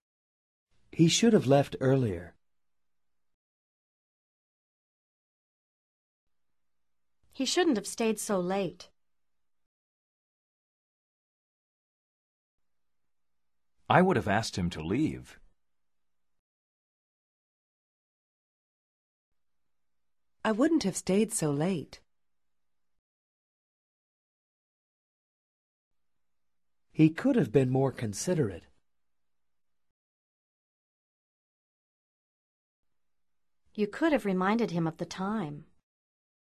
IMPORTANT: When pronouncing past modals, HAVE is reduced to /əv/. Therefore, the reduced HAVE sounds like preposition OF.